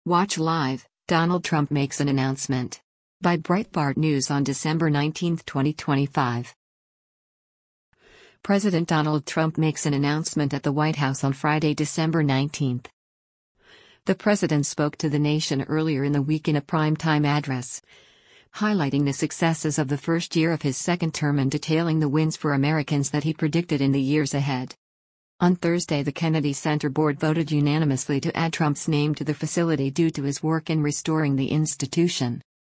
President Donald Trump makes an announcement at the White House on Friday, December 19.